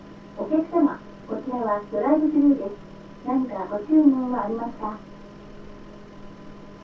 [casual speech + basic polite]